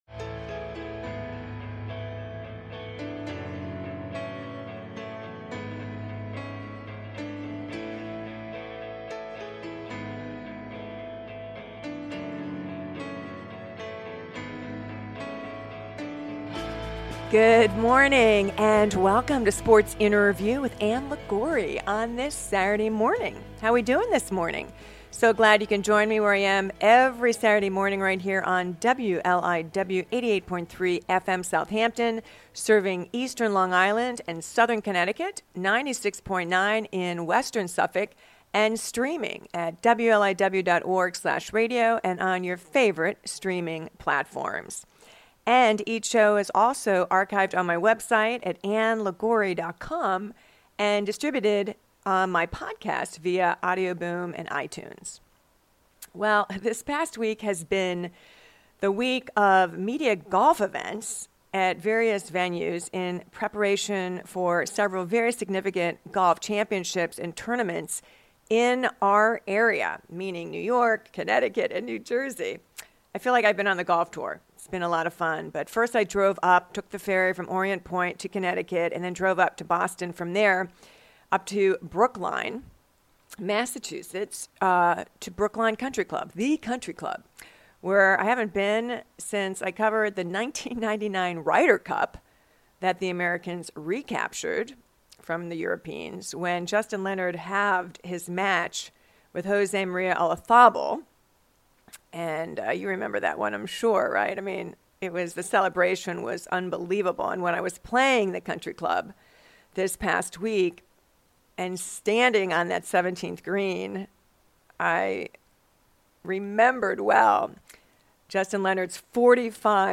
a heart to heart conversation